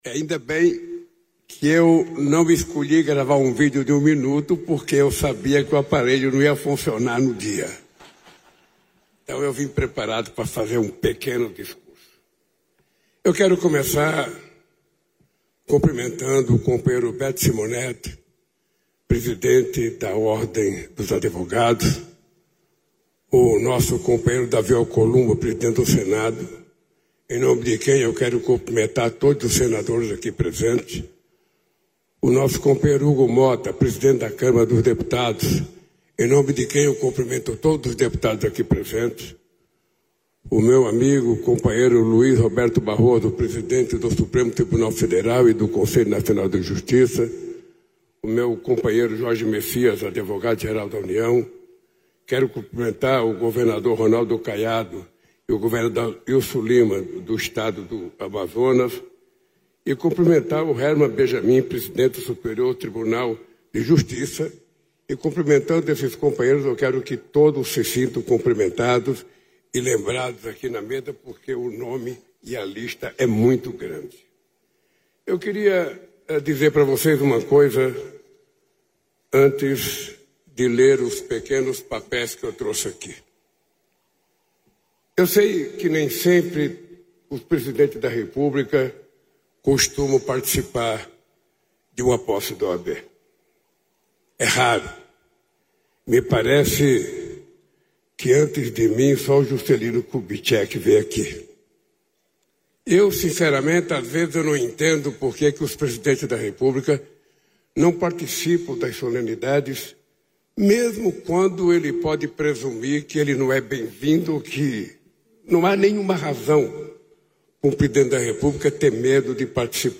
Íntegra do discurso do presidente da República, Luiz Inácio Lula da Silva, nesta quarta-feira (19), na cerimônia de inauguração da Barragem de Oiticica, em Jucurutu (RN).